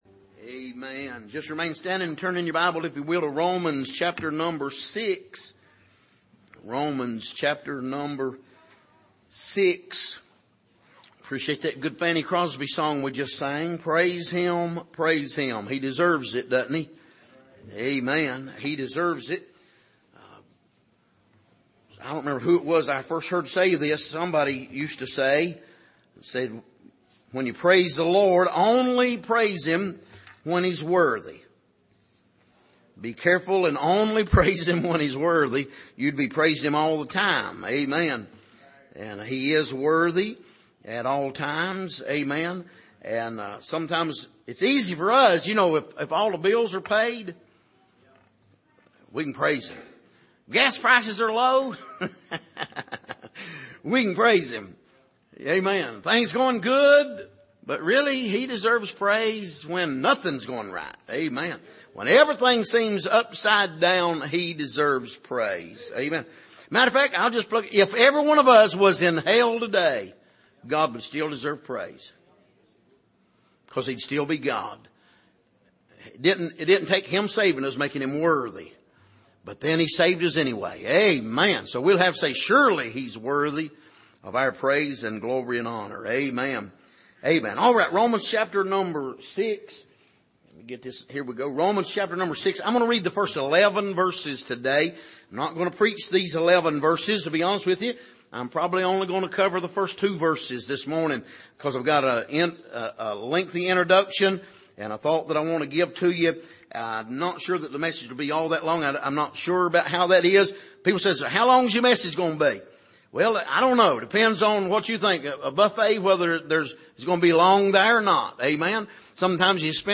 Passage: Romans 6:1-2 Service: Sunday Morning